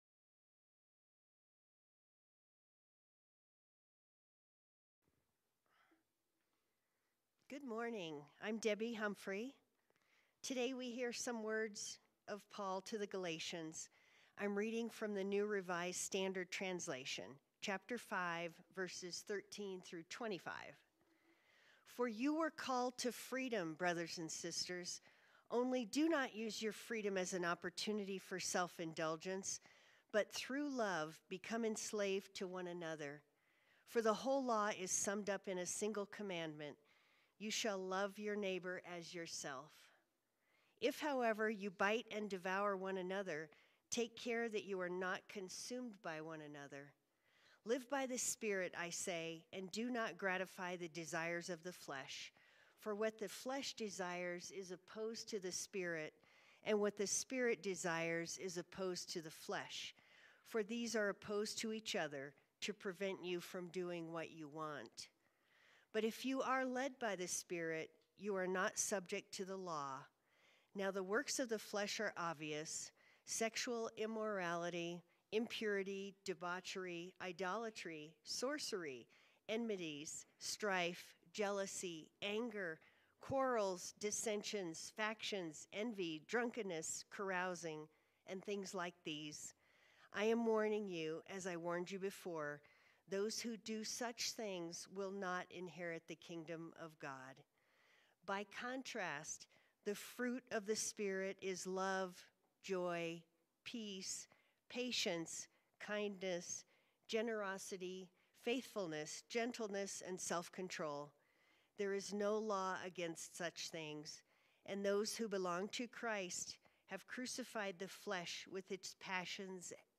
Sermons | United Methodist Church of Evergreen